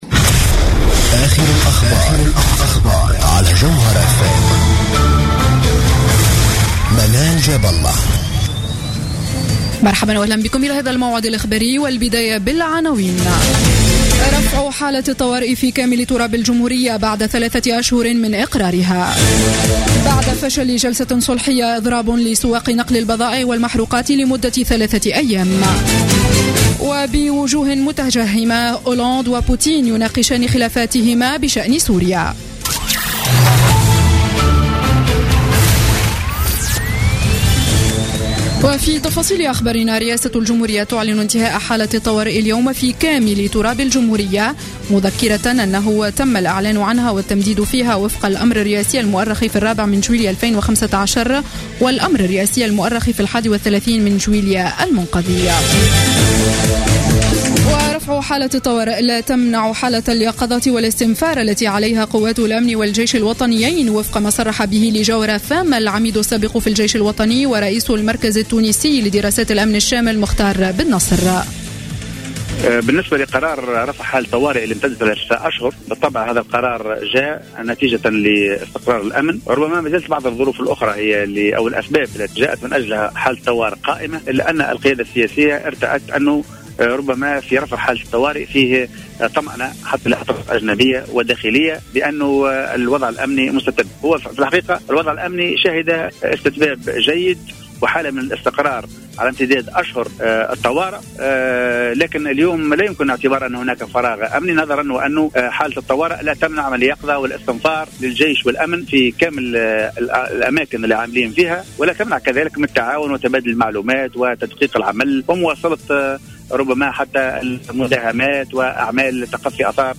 نشرة الأخبار السابعة مساء ليوم الجمعة 2 أكتوبر 2015